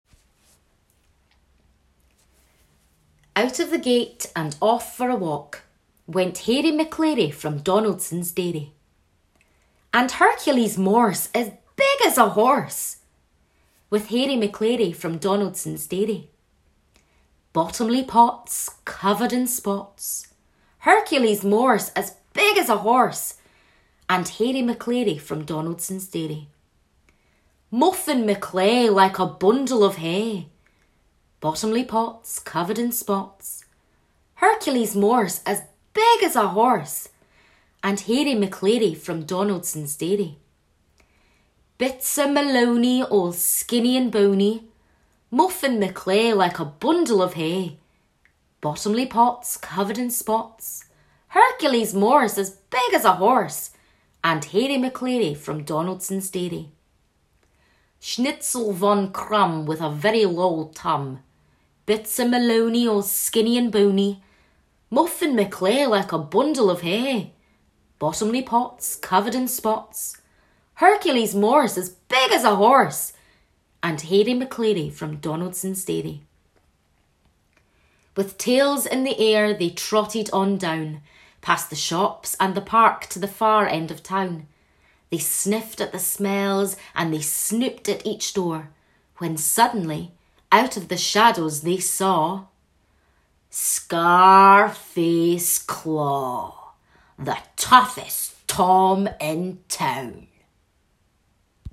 Voice Reel